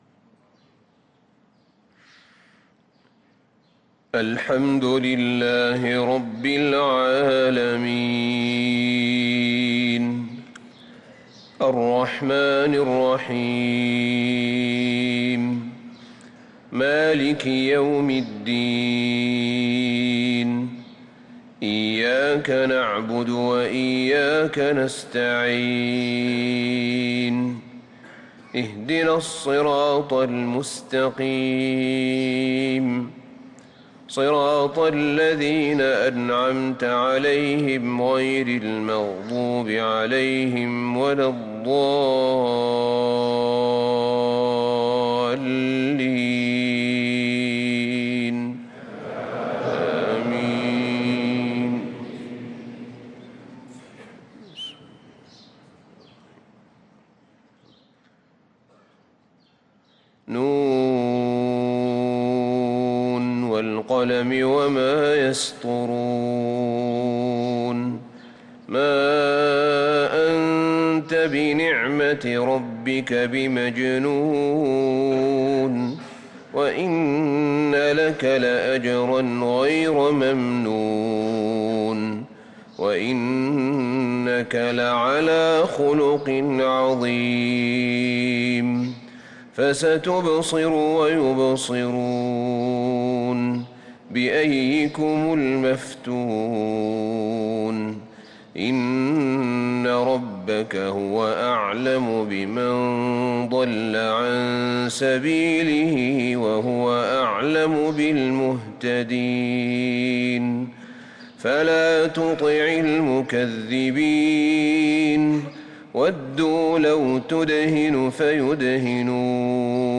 صلاة الفجر للقارئ أحمد بن طالب حميد 14 ربيع الأول 1445 هـ
تِلَاوَات الْحَرَمَيْن .